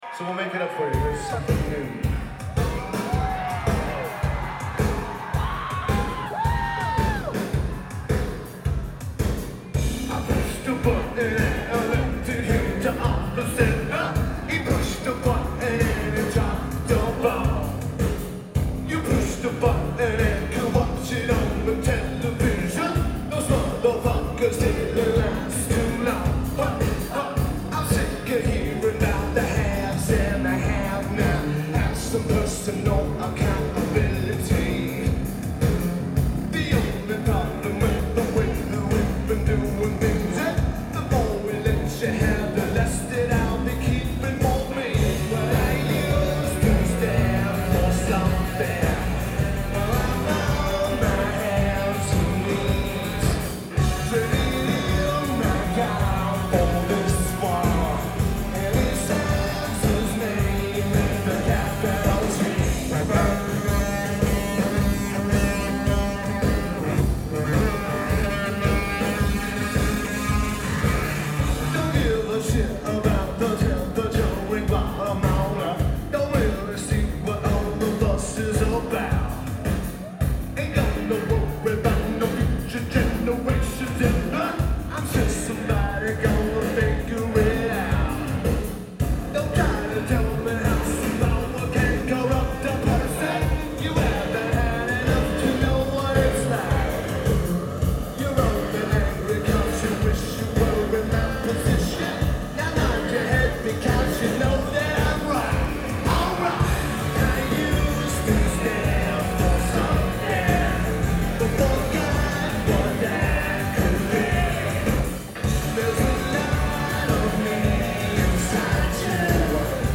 Metro Nightclub
Lineage: Audio - AUD (Sony ECM-717 + Iriver H320)